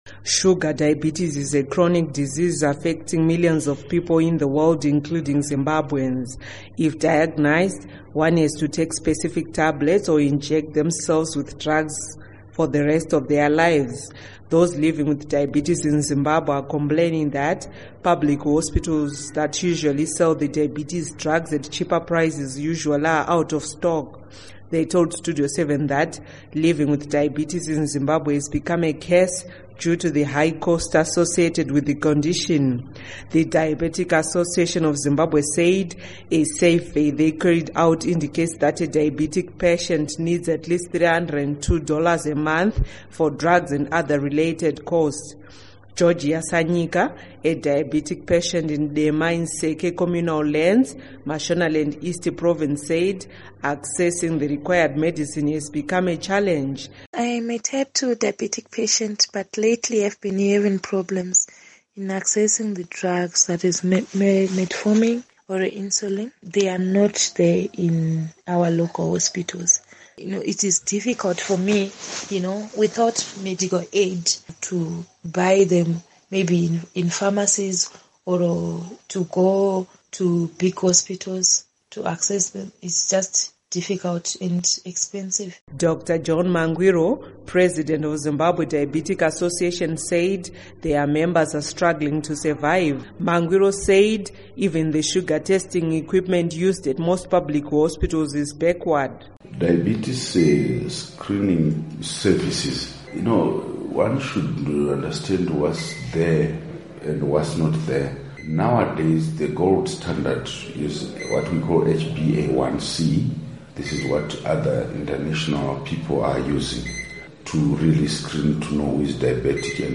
Report on Diabetes Drugs